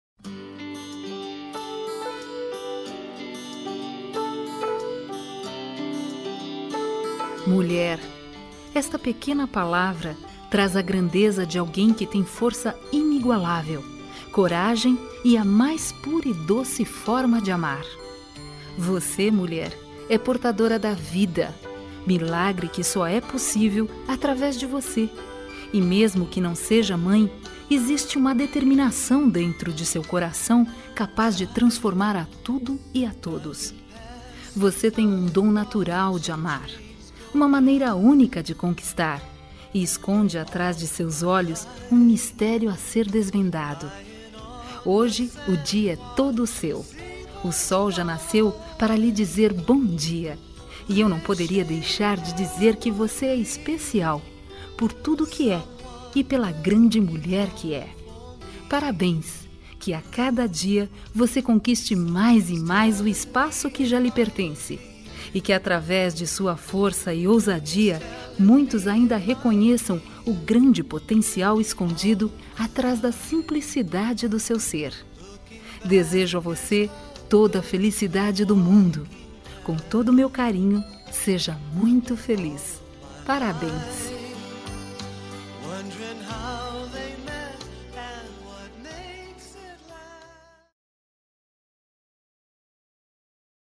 Dia Da Mulher Voz Feminina